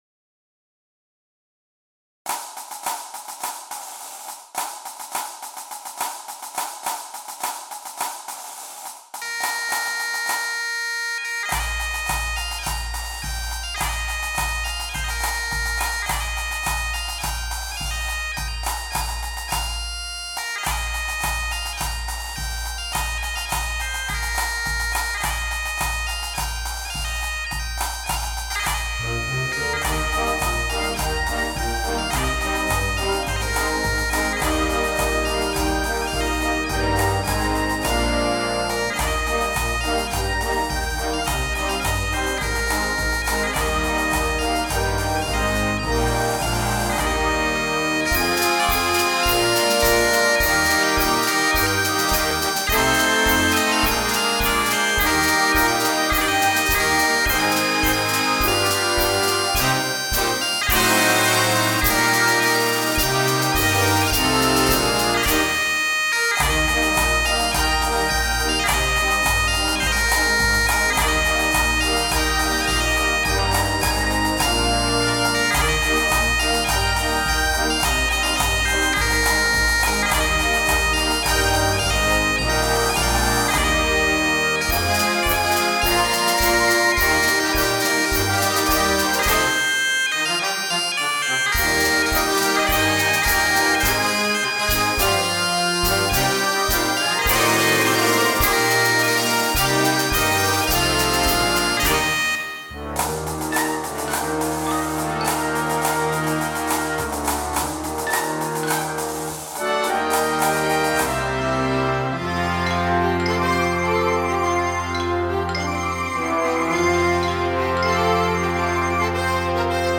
CategoryConcert Band & Bagpipes
Bagpipes
Piccolo
Flutes 1-2
Oboes 1-2
Bb Clarinets 1-2-3
Eb Alto Saxophones 1-2
Horns in F 1-2
Bb Trumpets 1-2-3
Tenor Trombones 1-2
Euphonium
Timpani
Side Drum
Xylophone
Tubular Bells